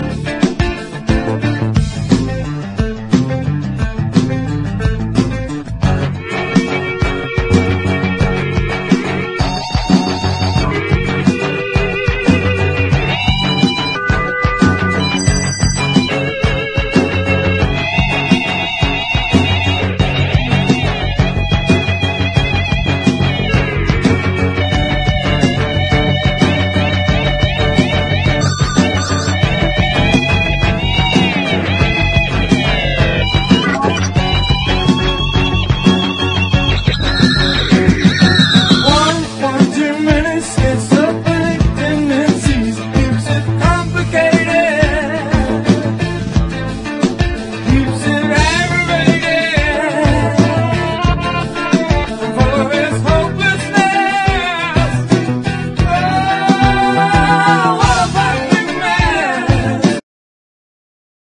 ROCK / 70'S / PROGRESSIVE ROCK